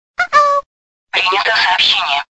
icq_prinjato_soobshenie.mp3.48.mp3